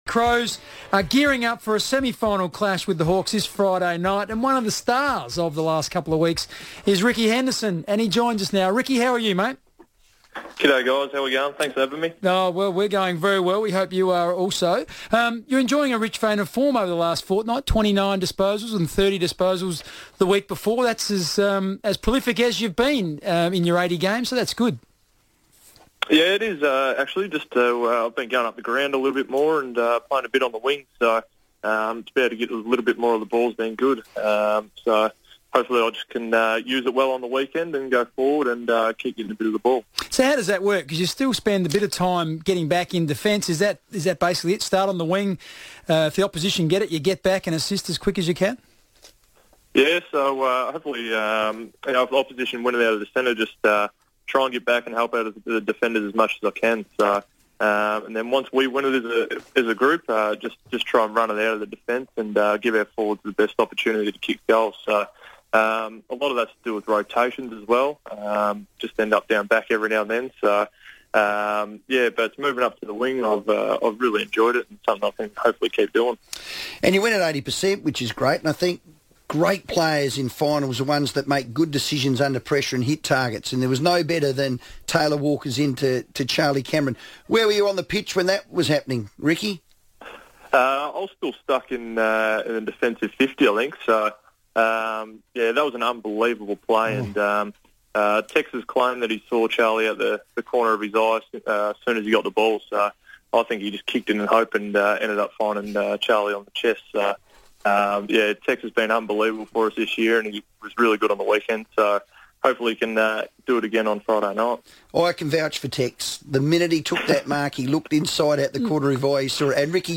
Crows defender Ricky Henderson spoke on the FIVEaa Sports Show ahead of Adelaide's Semi-Final clash with Hawthorn on Friday night at the MCG.